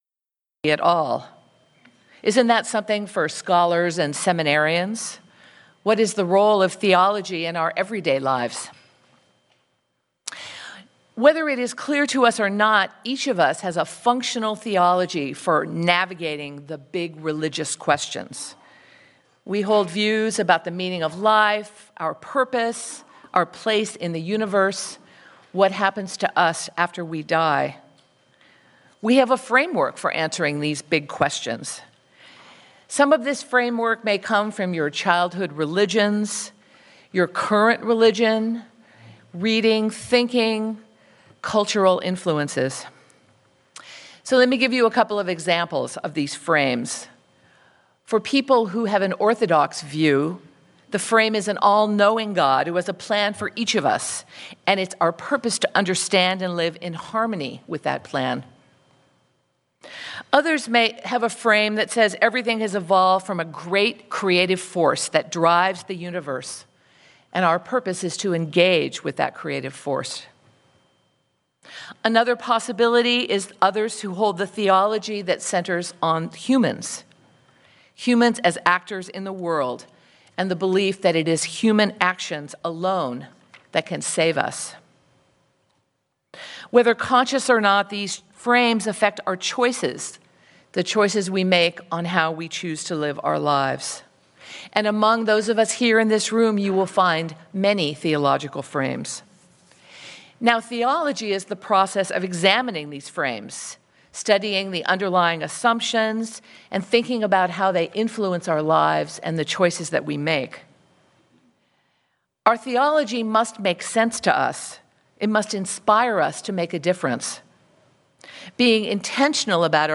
Sermon-A-Theology-for-the-21st-Century.mp3